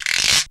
E2 GUIRO OP.wav